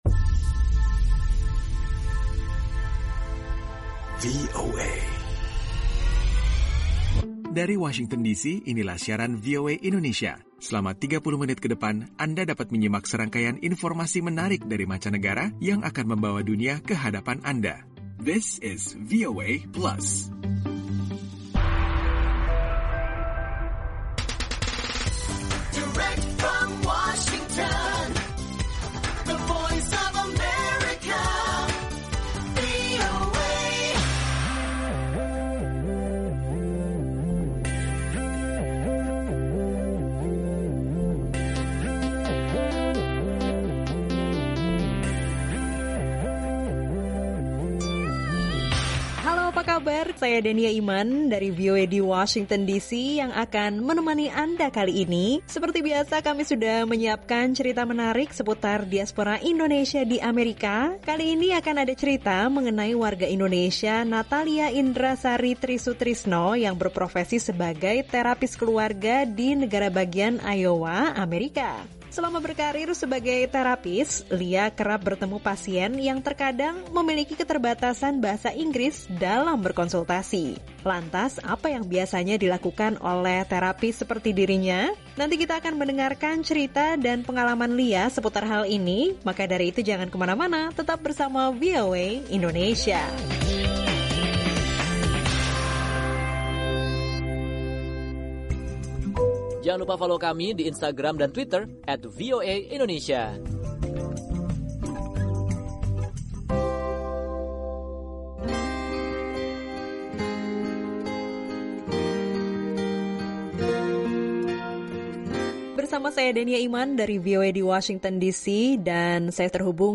Dalam VOA Plus kali ini, anda akan dapat menyimak obrolan bersama warga Indonesia yang bekerja sebagai family therapist di Amerika, adapula informasi tentang usaha mengadopsi binatang peliharaan di daerah Washington, DC.